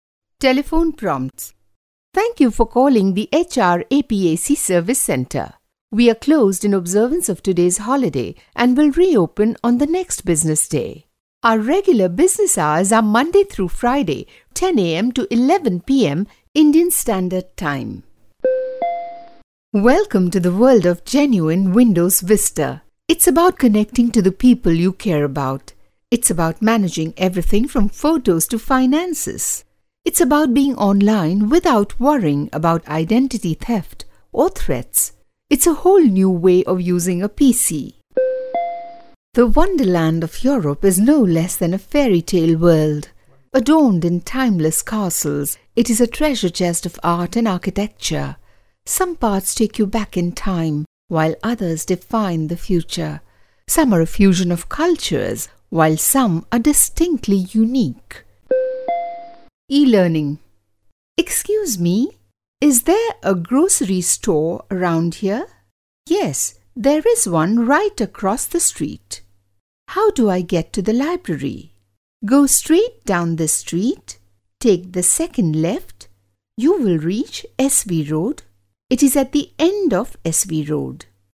indian female voice over artist
Sprechprobe: Industrie (Muttersprache):